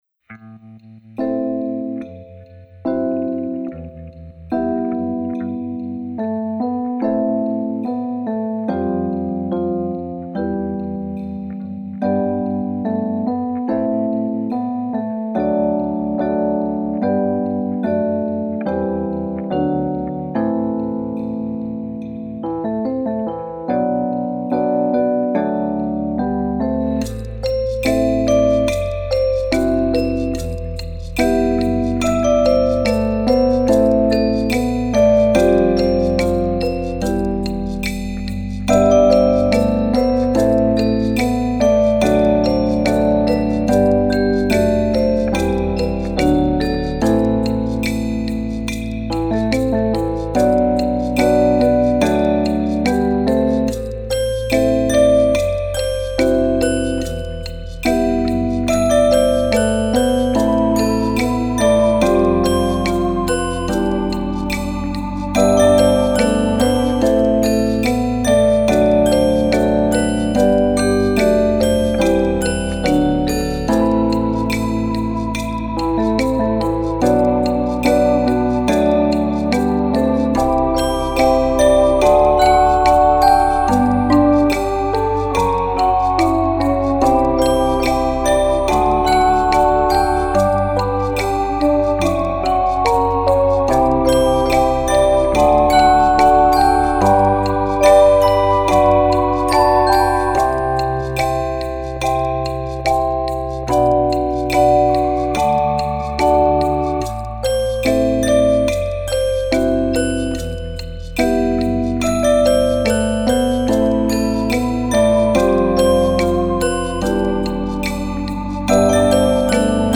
Genre: Instrumental, Music for Children